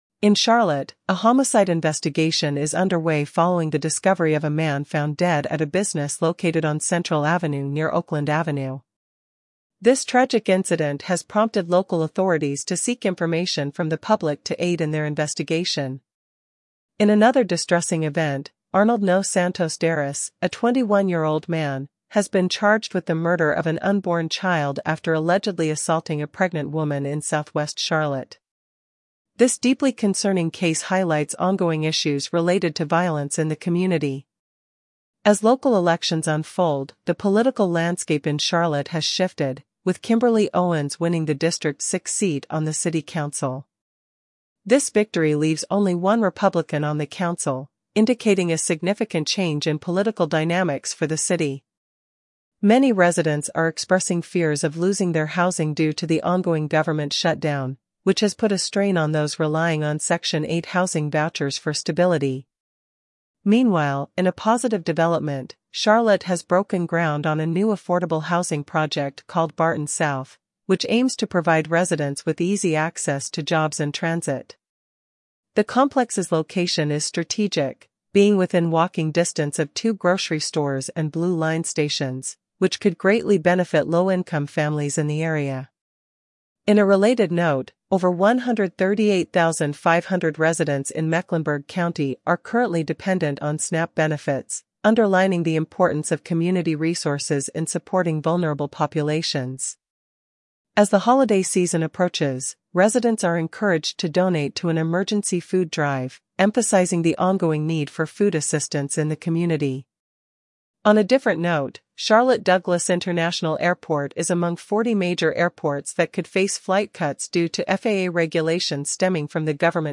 North Carolina News